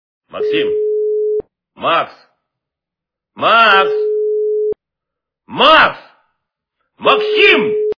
При прослушивании Именной звонок для Максима - Максим, Макс, Макс, Макс, Максим качество понижено и присутствуют гудки.